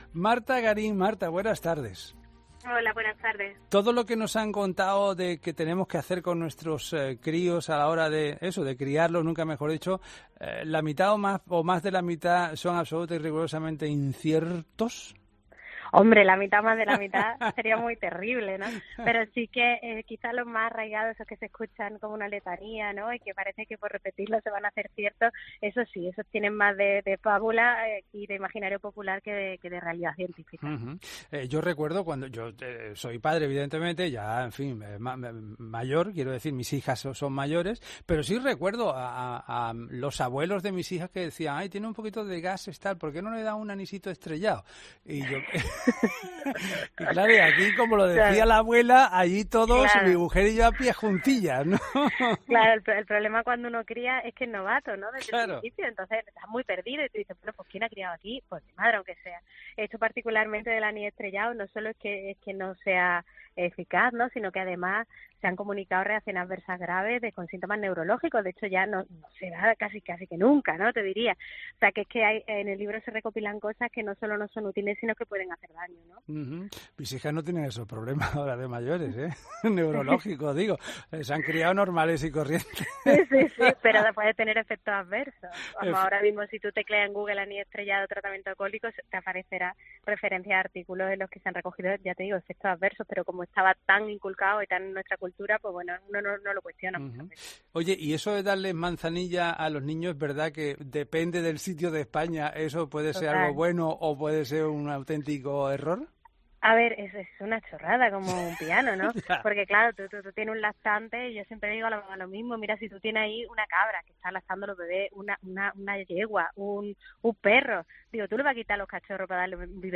Para conocer mejor todos estos mitos escucha la entrevista que se adjunta en la foto de portada de esta noticia